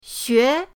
xue2.mp3